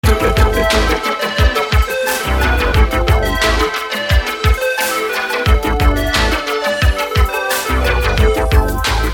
• Качество: 192, Stereo
электронная музыка
без слов